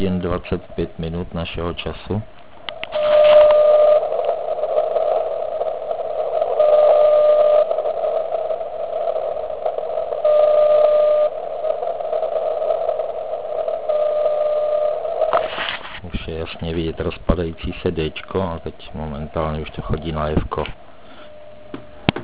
Vysílal zkušebně na 3536.1 KHz.
V Liberci: